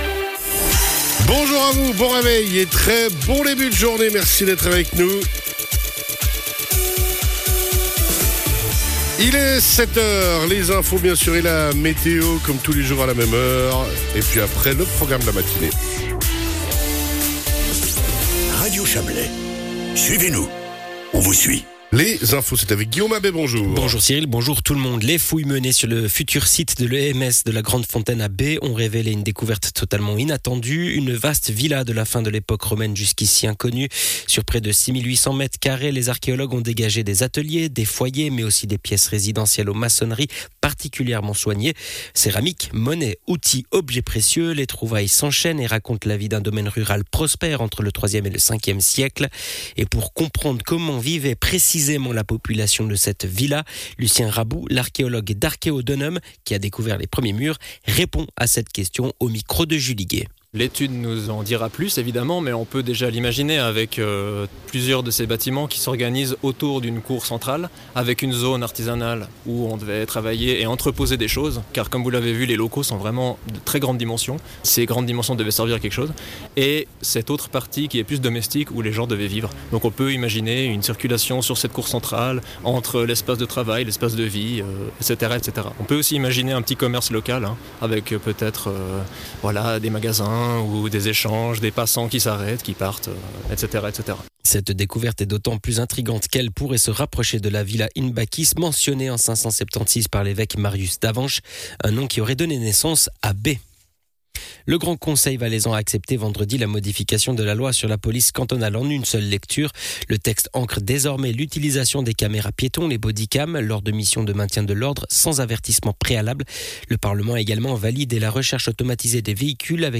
Le journal de 7h00 du 17.11.2025